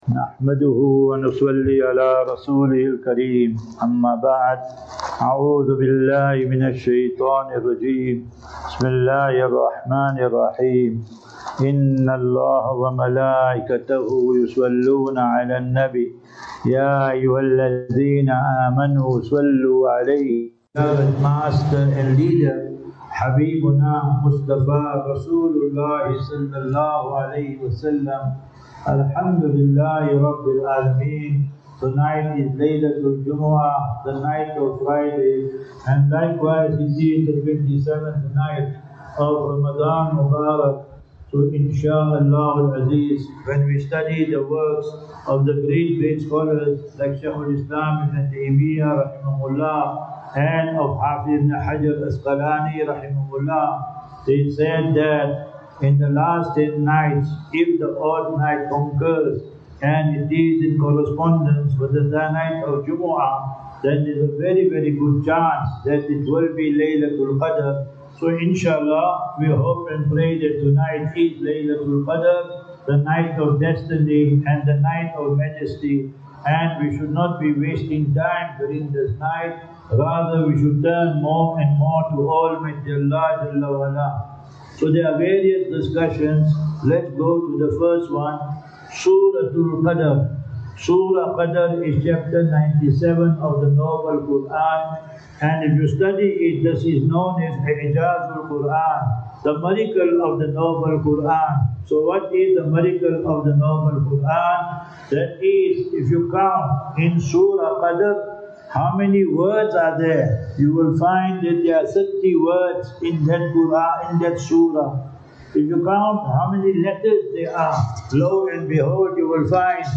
Lectures
Barberton Masjid